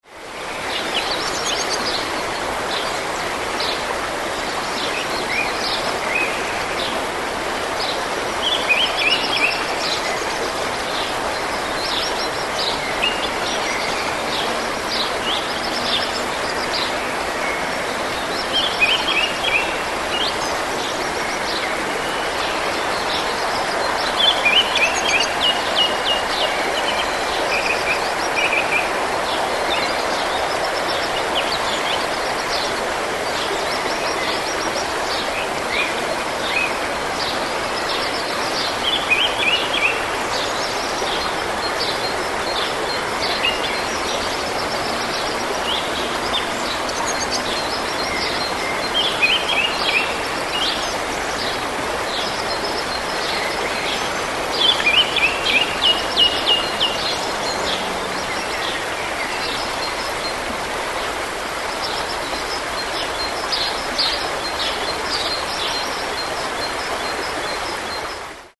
Звуки реки